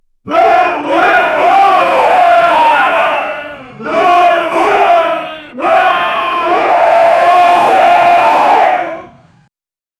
eine-riesige-ork-armee-ru-s25jzy6i.wav